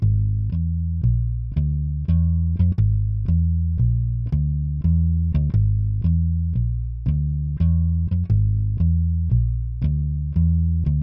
嘣嘣啪啪老式低音炮
Tag: 87 bpm Hip Hop Loops Bass Guitar Loops 950.47 KB wav Key : Unknown